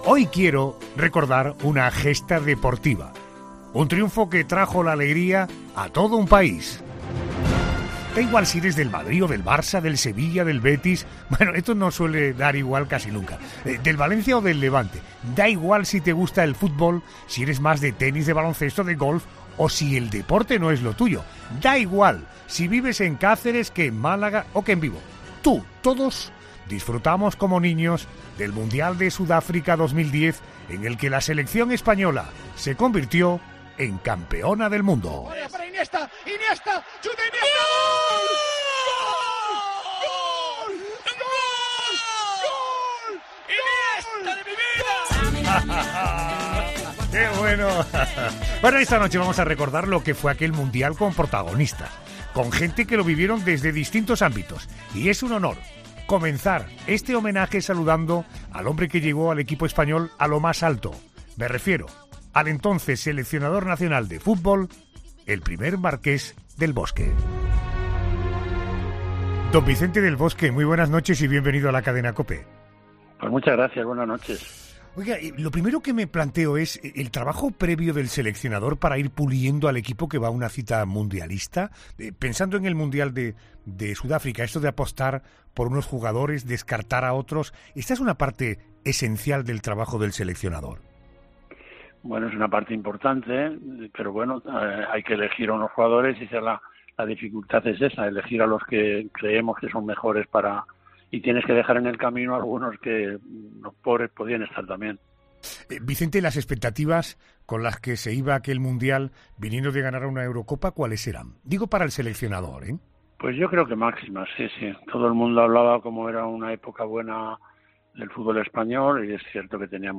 En homaneja a la victoria española en aquel campeonato hace doce años, Arjona ha recibido al hombre que llevó al equipo español a lo más alto: al entonces seleccionador nacional de fútbol, Vicente del Bosque .